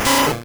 Cri de Rattatac dans Pokémon Rouge et Bleu.